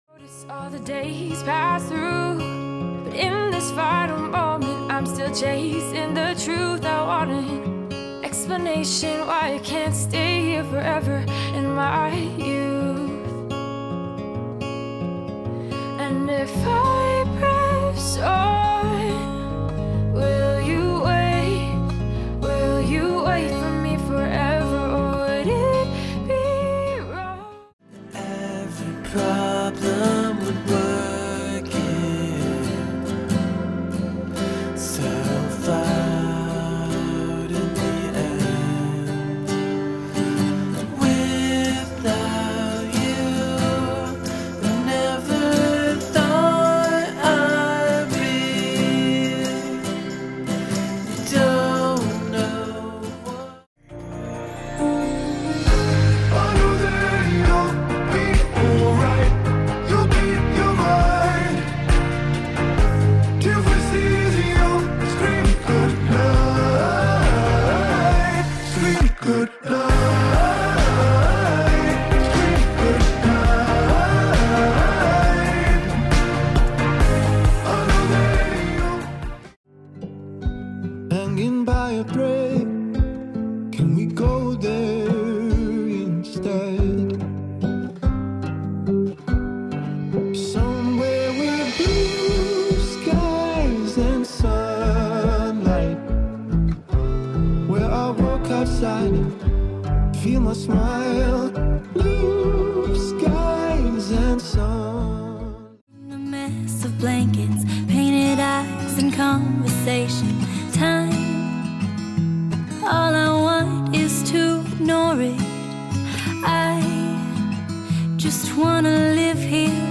styl - ballads/chillout/lounge